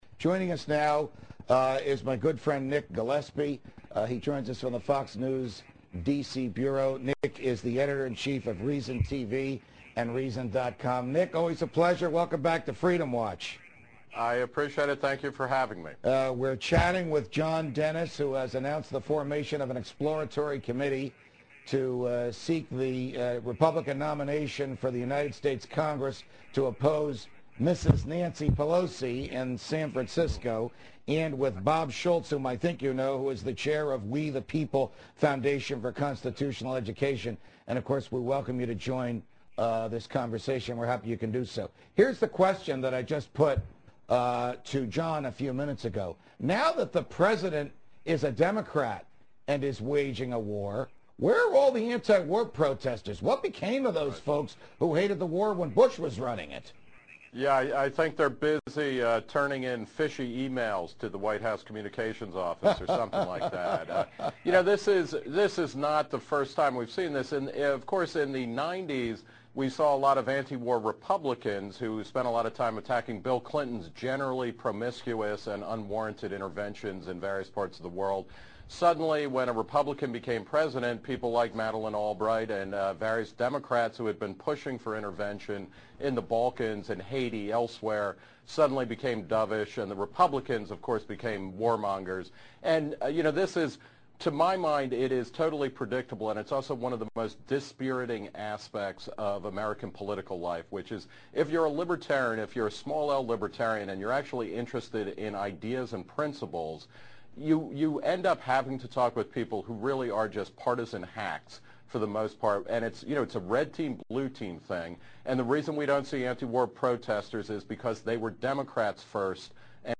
On August 12, Reason's Nick Gillespie talked with Judge Andrew Napolitano, the host of the Fox News Channel's web show Freedom Watch. Among the topics covered: the end of left-wing anti-war demonstrations, the pushback against federalism when it comes to medical marijuana dispensaries in California and elsewhere, and the need to squeeze politics down to the smallest size in everyday life.